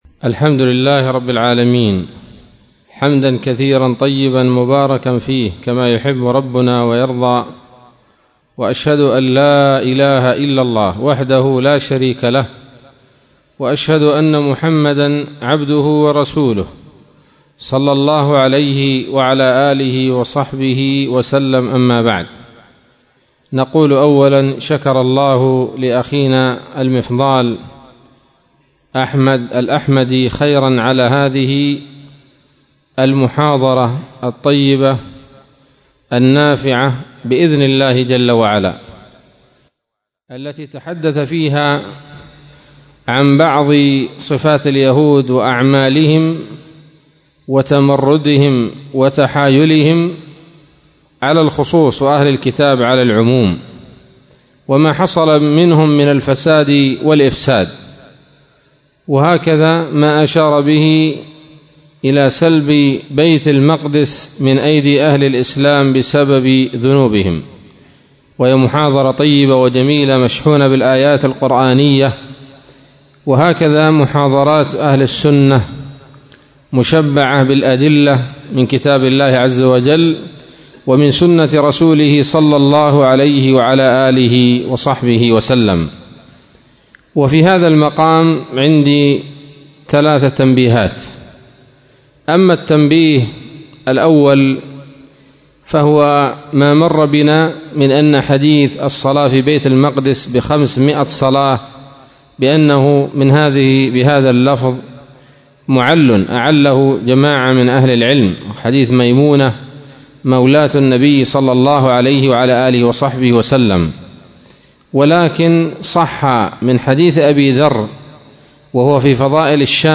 كلمة قيمة
بدار الحديث بصلاح الدين